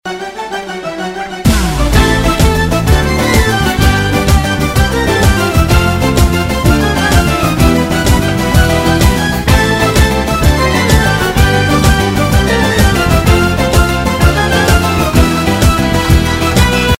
Категория: Шансон | Дата: 10.12.2012|